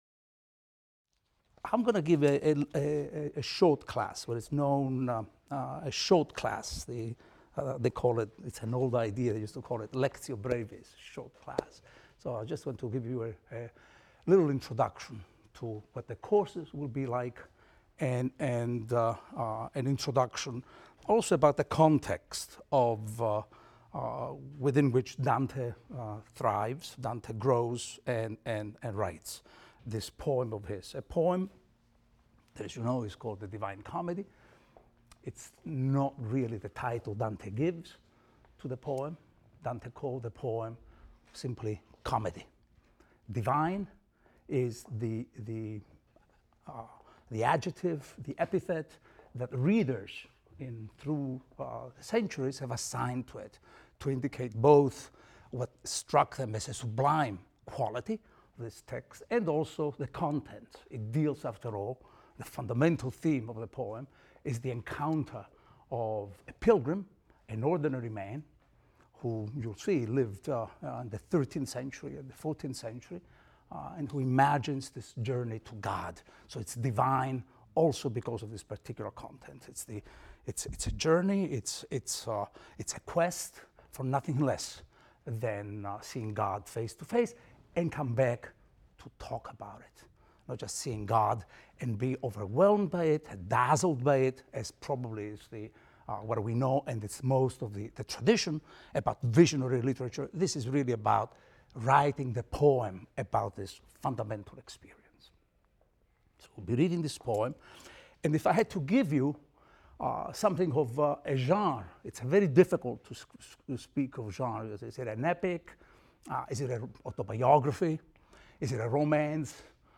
ITAL 310 - Lecture 1 - Introduction | Open Yale Courses